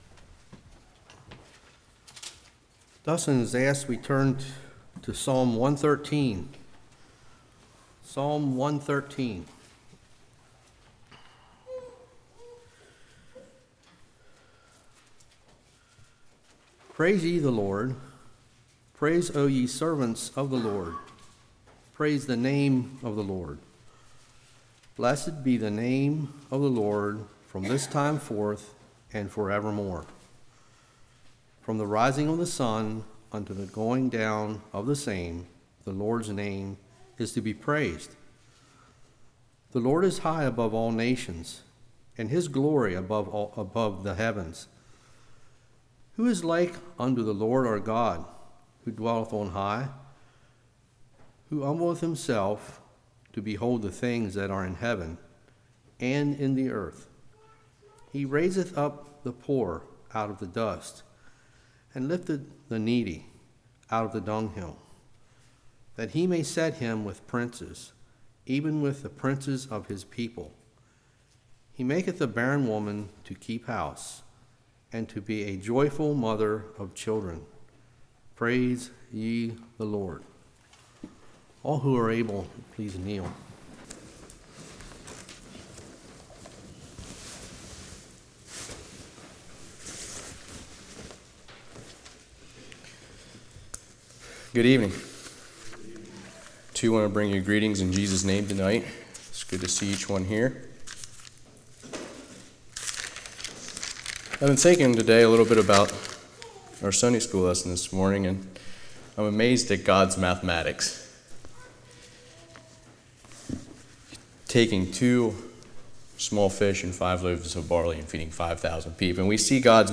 Psalms 113:1-9 Service Type: Evening Praising Is a Command What is Praise?